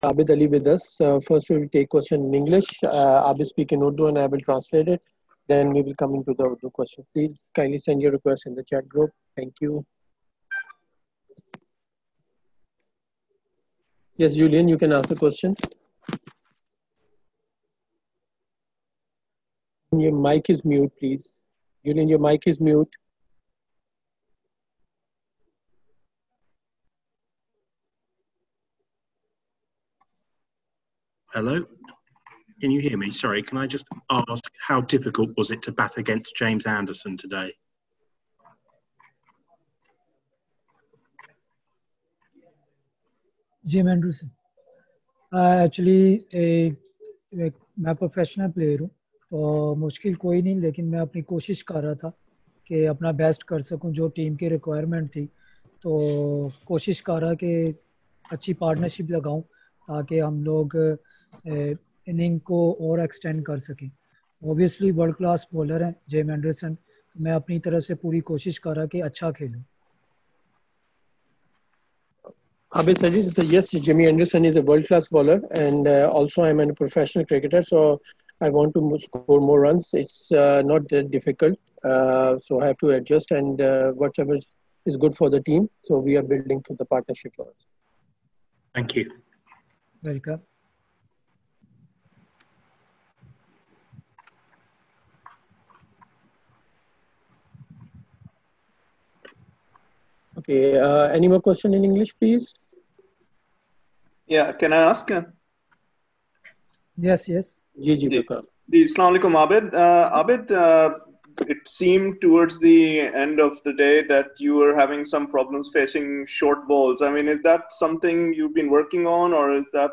Abid Ali holds virtual media conference after fourth day’s play